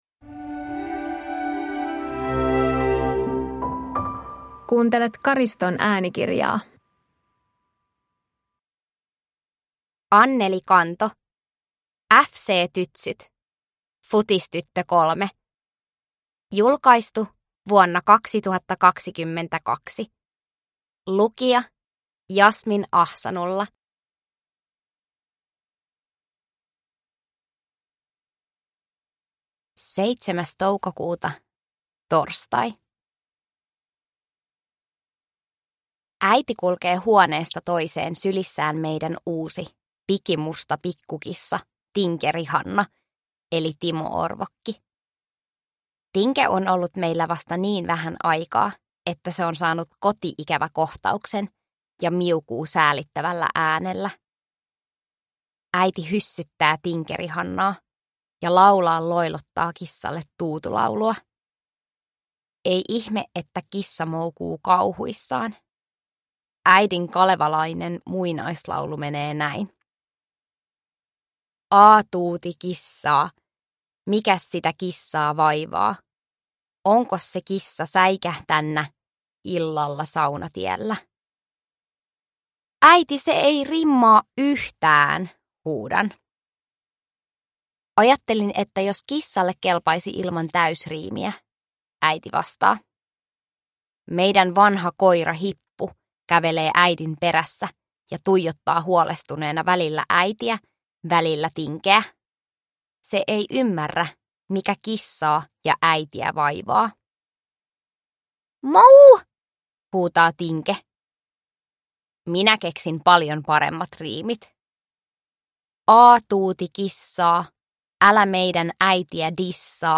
FC Tytsyt – Ljudbok